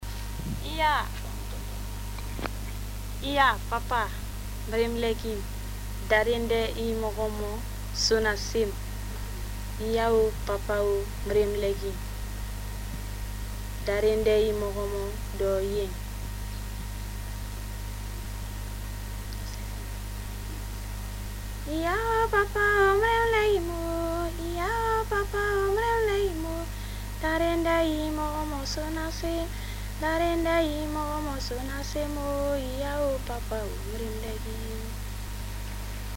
Iya oo papa oo (cançó Baga)
Enregistrament sense tractar digitalment i amb recitat previ de la lletra (36'', 567 KB):
Les oo són purament eufòniques i ornamentals.
T'envio un fitxer mp3 amb la cançó recitada primer i cantada després.